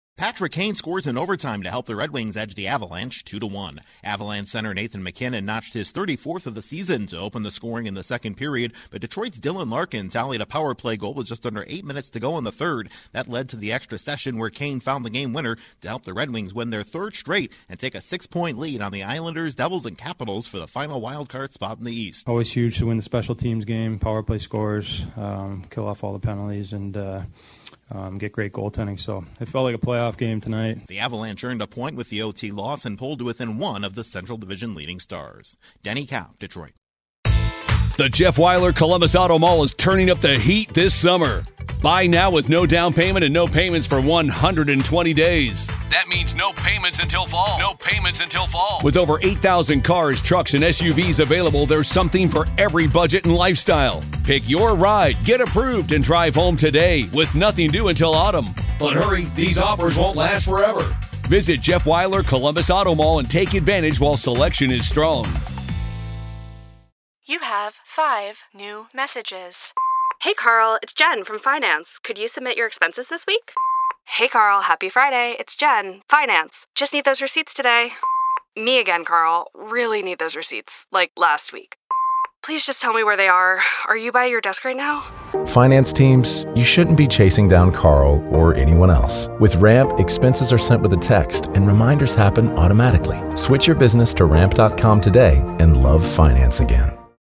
The Red Wings come from behind to upend the Avalanche. Correspondent